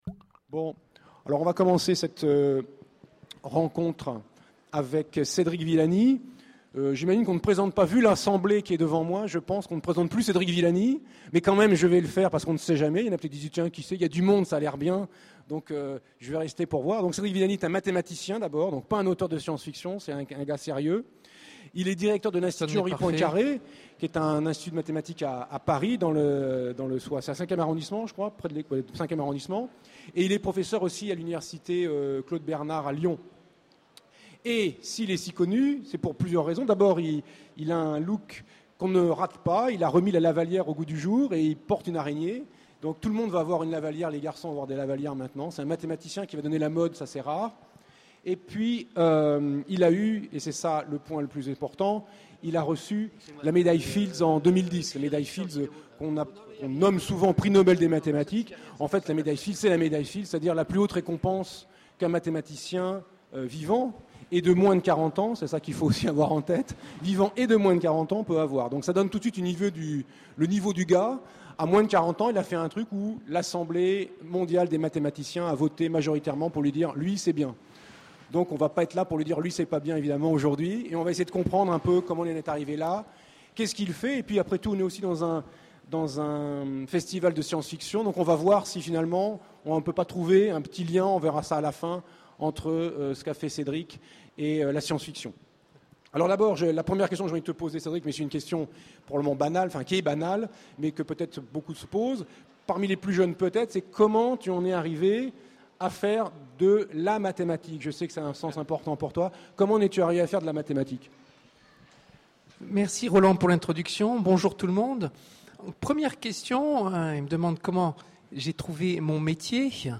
Utopiales 12 : Conférence Rencontre avec Cédric Villani
Conférence